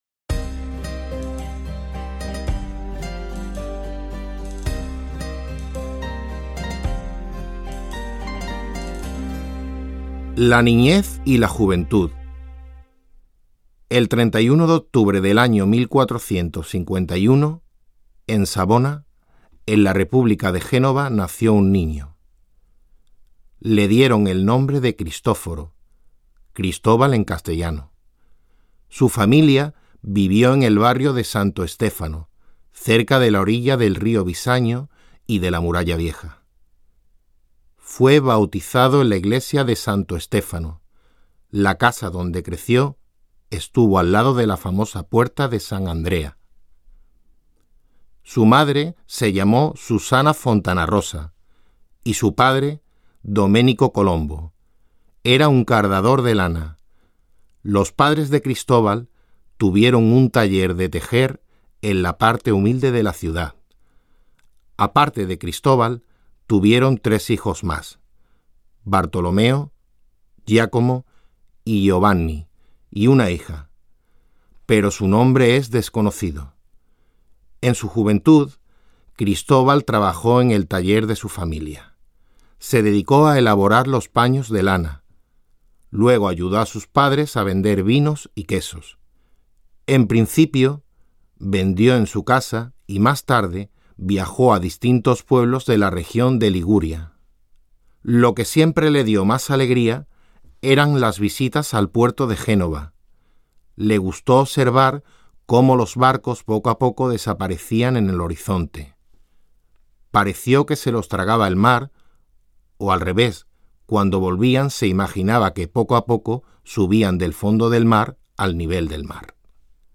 Audio kniha
Ukázka z knihy
Audio verzi namluvil rodilý mluvčí.